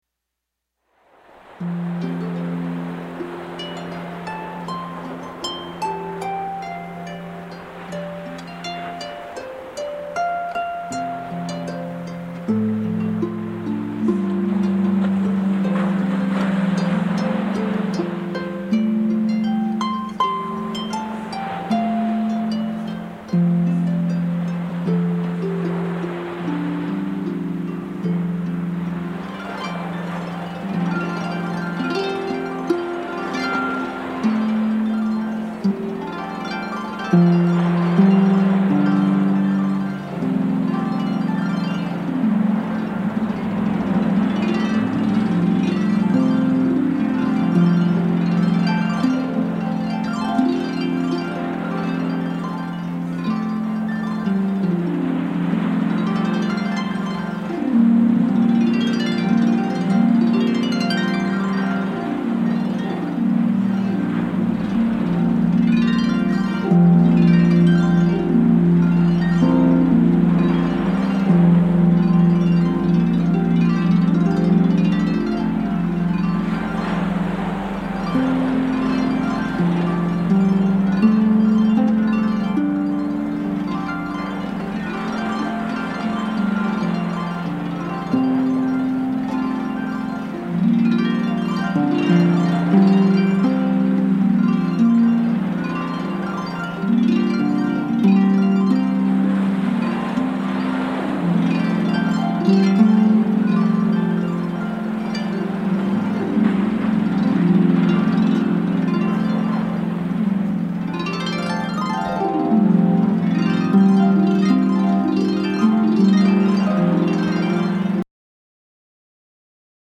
LUXURIOUS SOOTHING HARP MUSIC